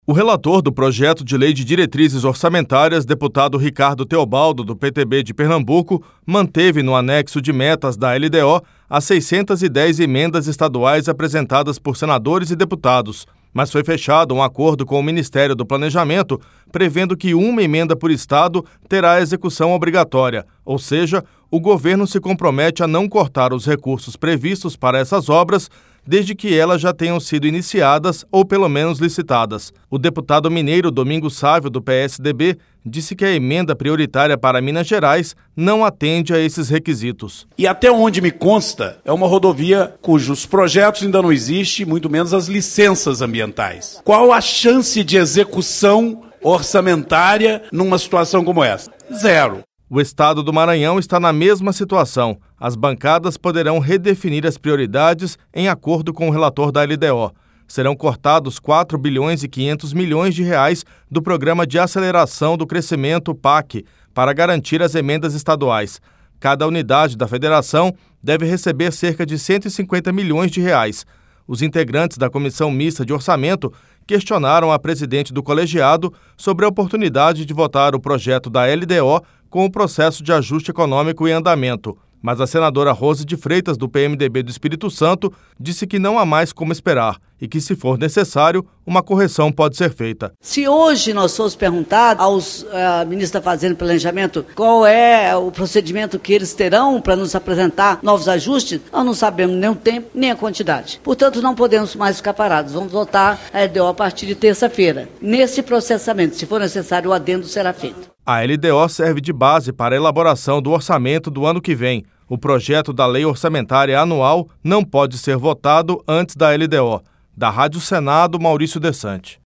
Rádio Senado
RadioAgência